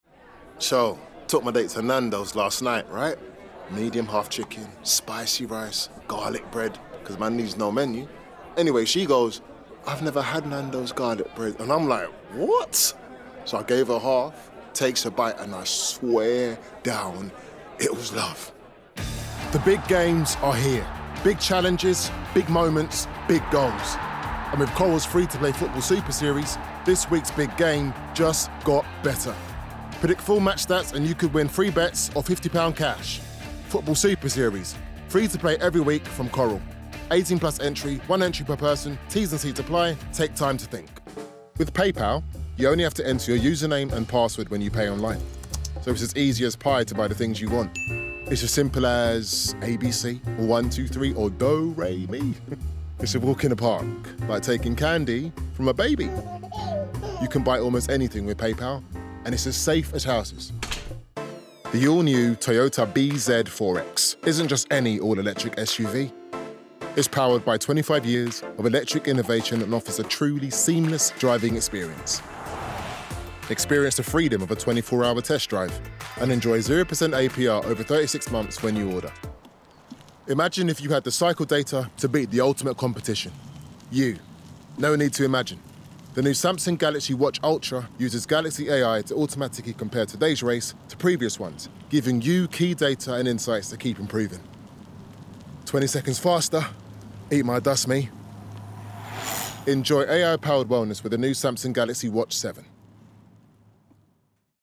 30's London/Neutral, Cool/Laidback/Edgy